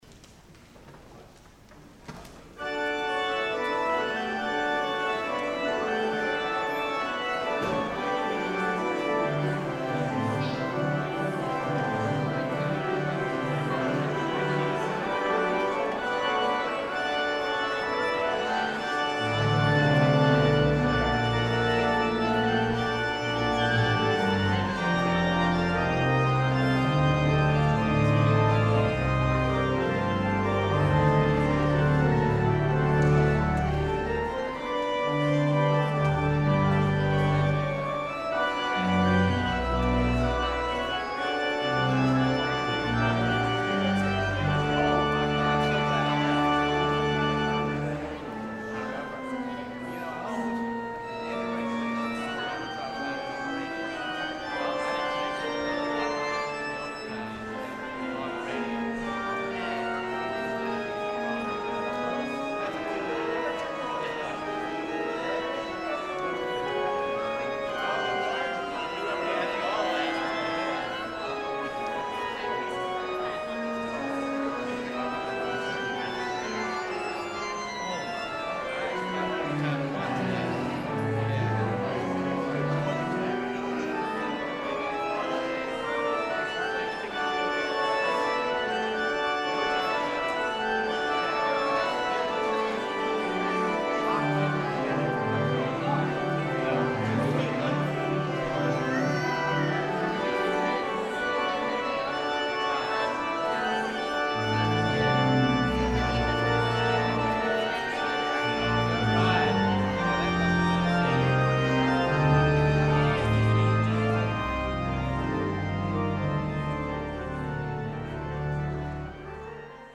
guest organist